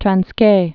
(trăns-kā, -kī)